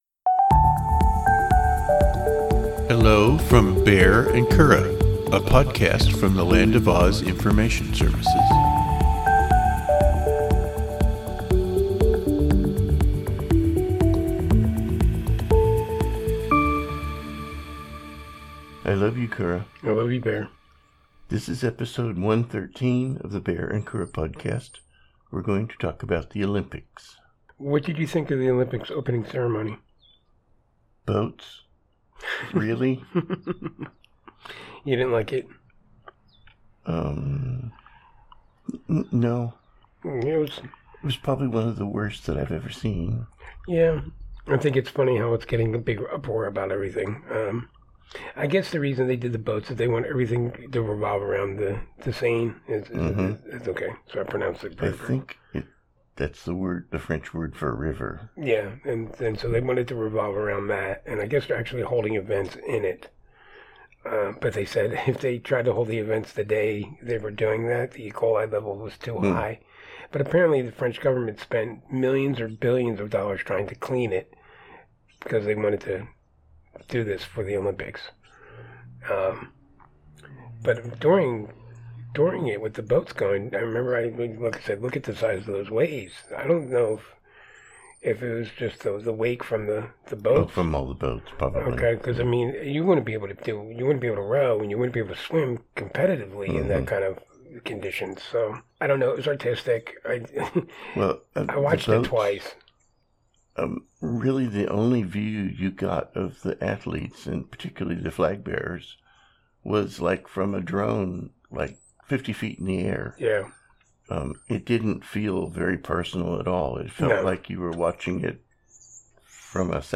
Two married gay guys discuss life, synergy, and the pursuit of happiness.